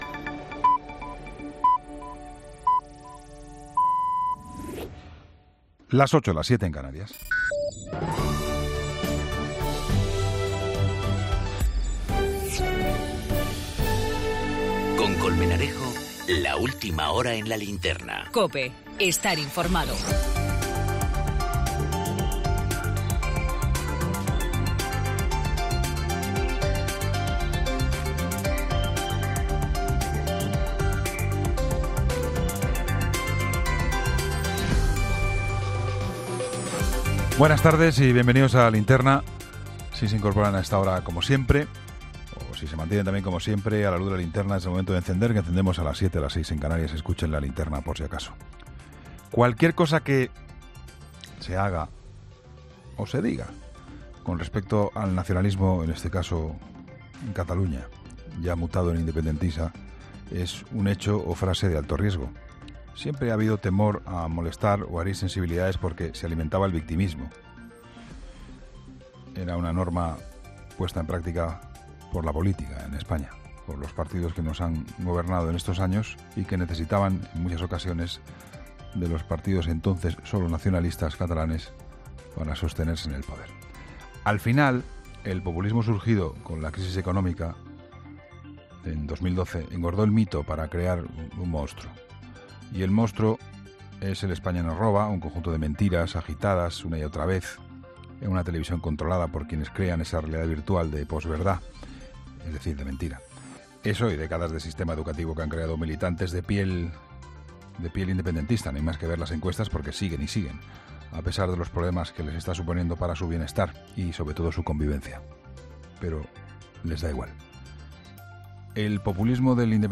La crónica